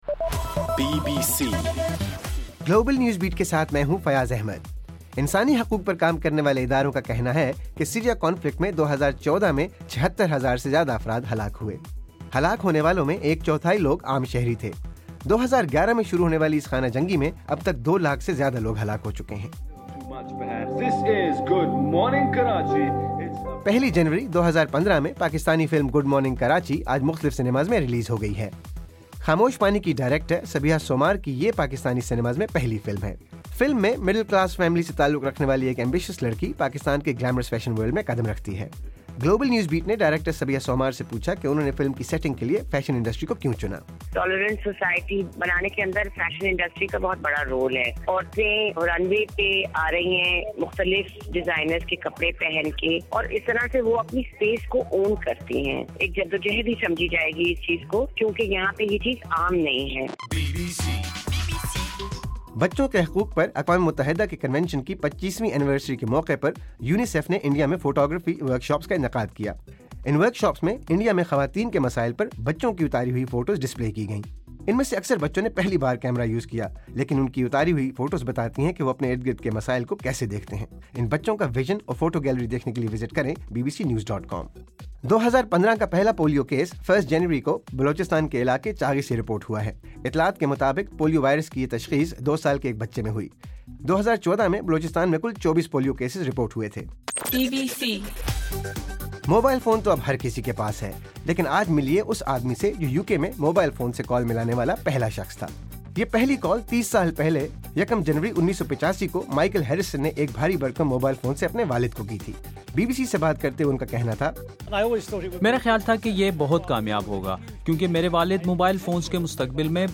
جنوری 01: رات 10 بجے کا گلوبل نیوز بیٹ بُلیٹن